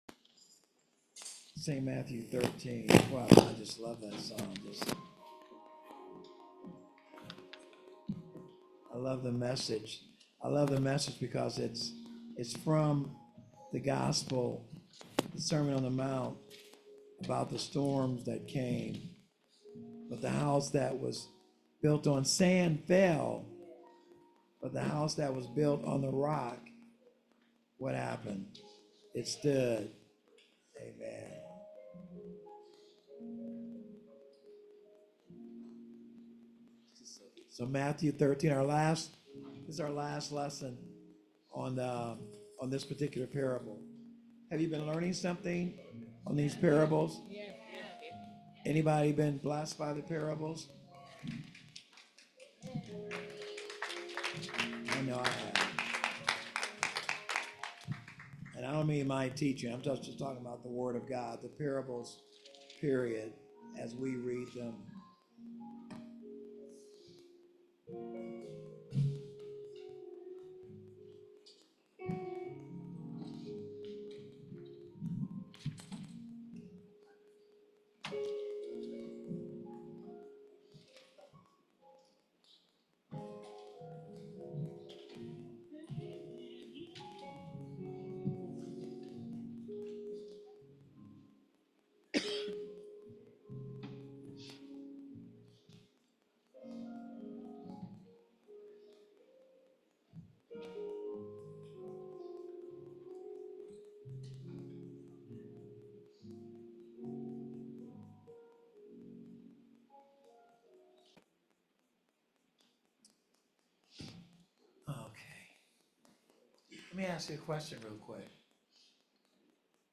Sowers, Seed, & Soil (pt.3) – Believer's Bible Church (Euclid, Ohio)
Sermon Handout